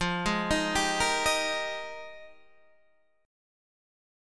Fm11 chord